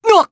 Arch-Vox_Damage_jp_03.wav